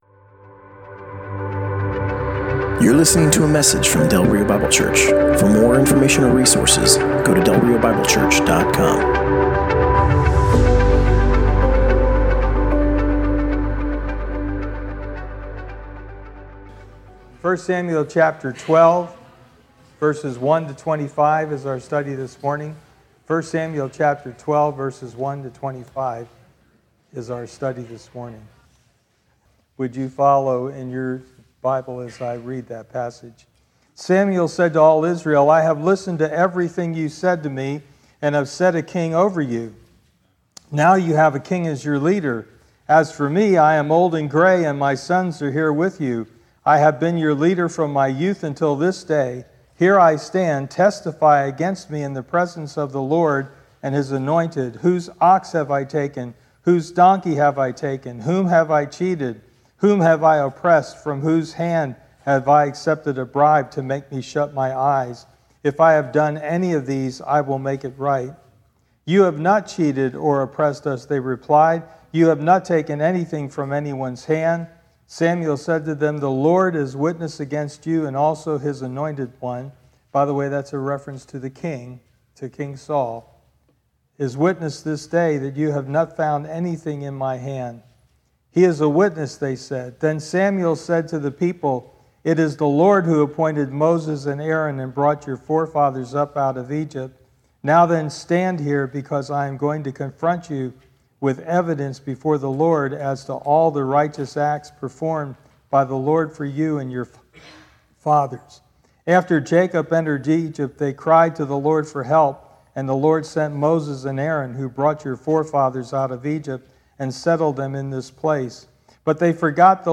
Passage: 1 Samuel 12: 1-25 Service Type: Sunday Morning